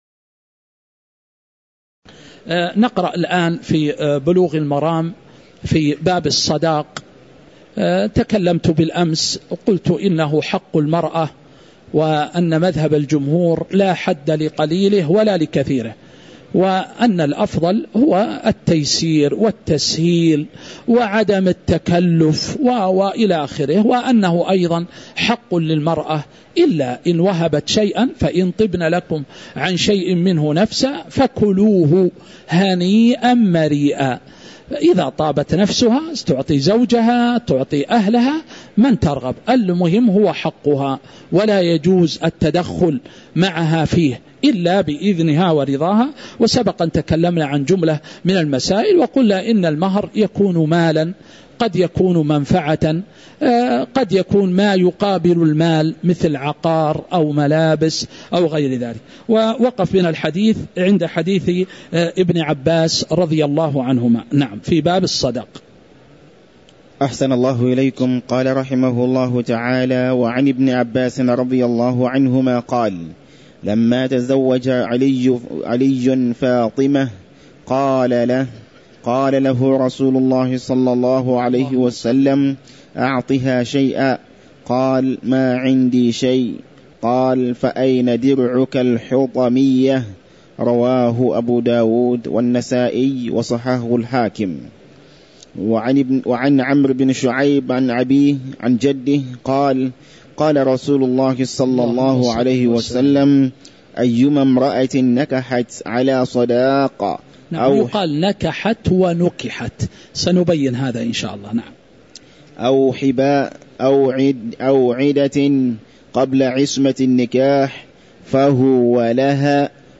تاريخ النشر ٨ شوال ١٤٤٦ هـ المكان: المسجد النبوي الشيخ